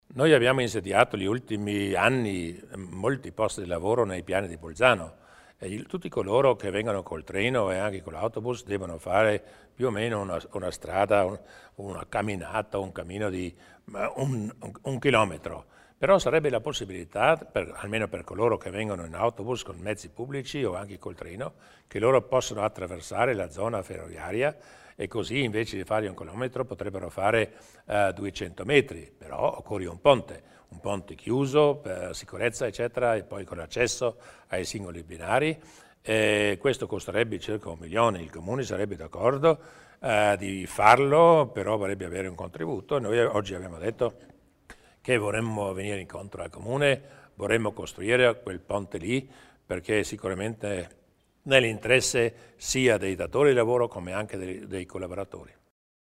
Il Presidente Durnwalder illustra il progetto per collegare i Piani di Bolzano alla stazione